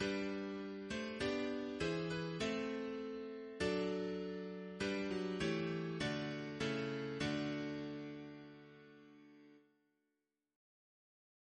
Single chant in G Composer: Richard Wayne Dirksen (1921-2003), Organist of Washington Cathedral Note: antiphon for Psalm 8